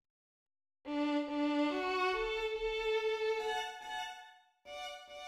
waltz
in MIDI